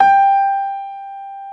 55p-pno28-G4.wav